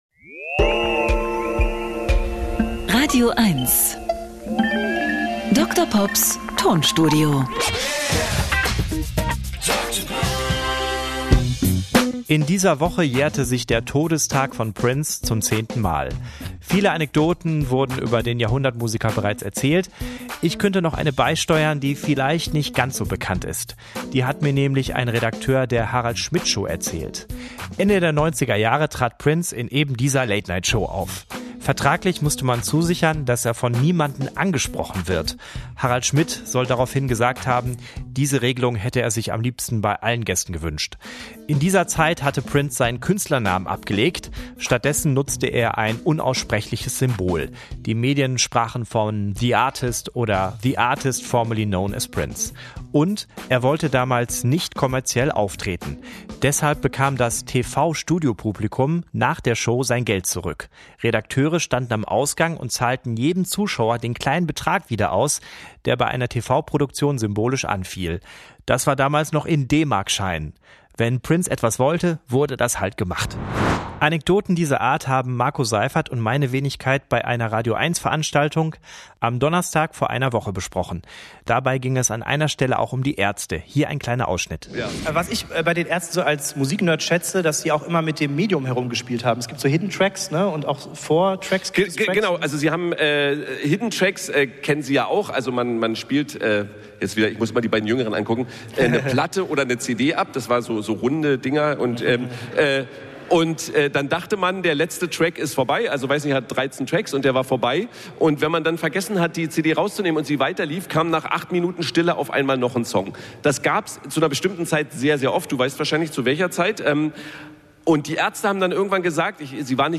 Er therapiert mit Musiksamples und kuriosen, aber völlig wahren Musikfakten.
Comedy